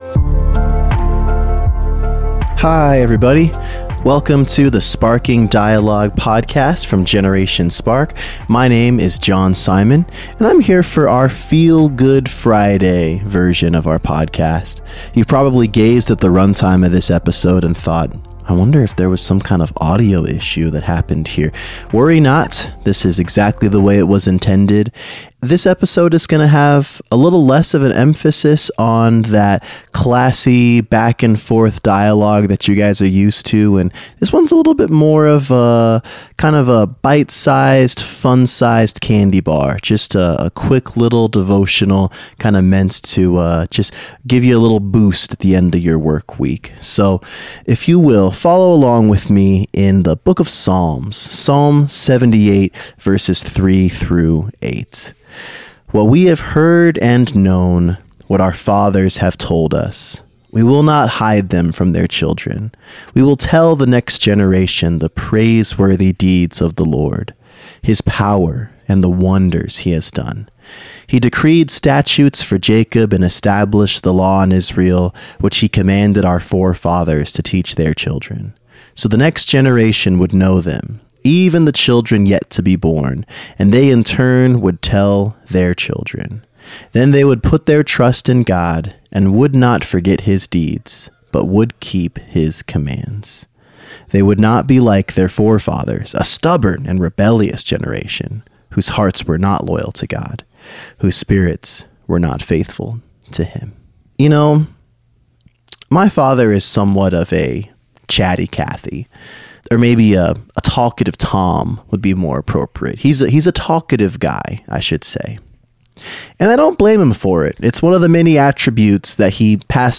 The Art of Storytelling: A Devotional